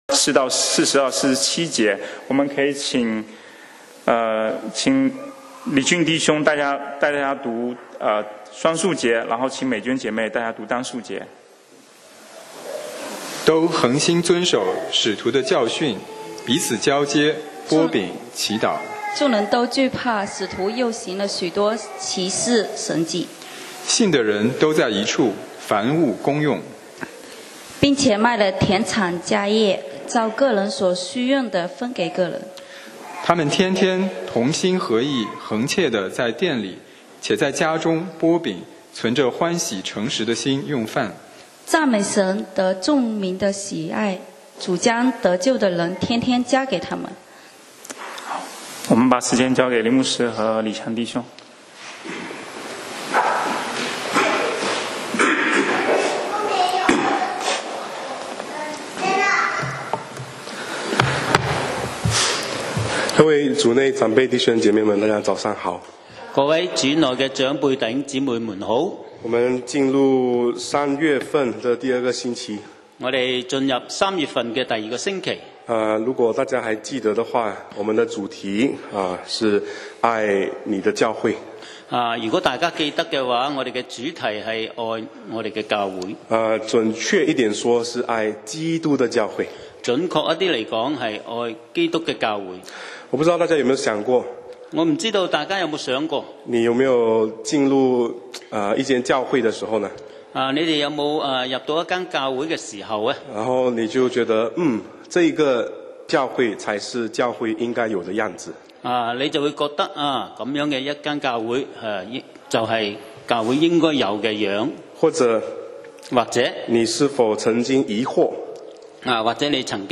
講道 Sermon 題目 Topic：使徒教会 經文 Verses：使徒行传 2：42-47 42都恒心遵守使徒的教训，彼此交接，掰饼，祈祷。